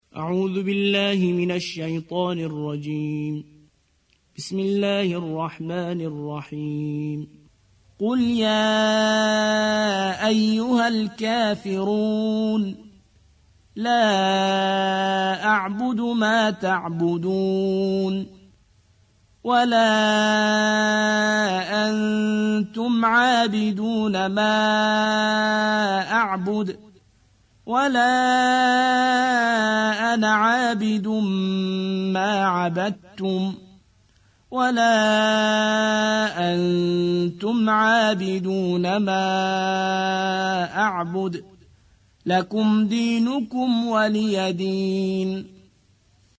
109. سورة الكافرون / القارئ